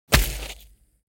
دانلود آهنگ بیابان 13 از افکت صوتی طبیعت و محیط
دانلود صدای بیابان 13 از ساعد نیوز با لینک مستقیم و کیفیت بالا
جلوه های صوتی